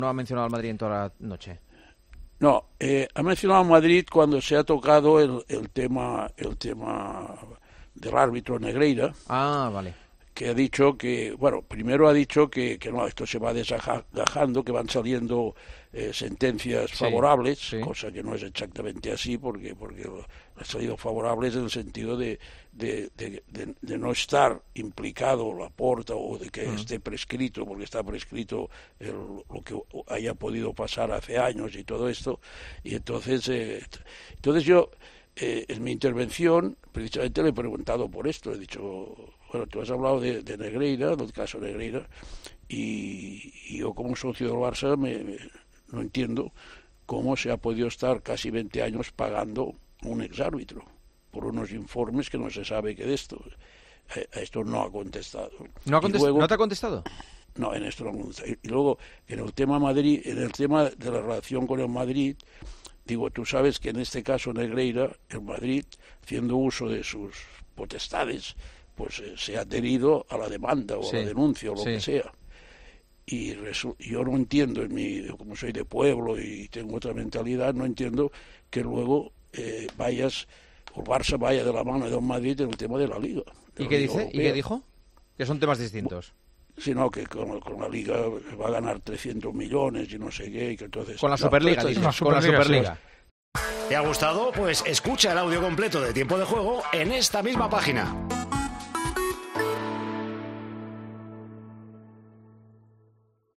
El comentarista de Tiempo de Juego y socio azulgrana explicó en El Partidazo de COPE en qué consistía la reunión y analizó el discurso del presidente azulgrana.
El comentarista de Tiempo de Juego, además, reveló a Juanma Castaño qué pregunto a Laporta durante la reunión.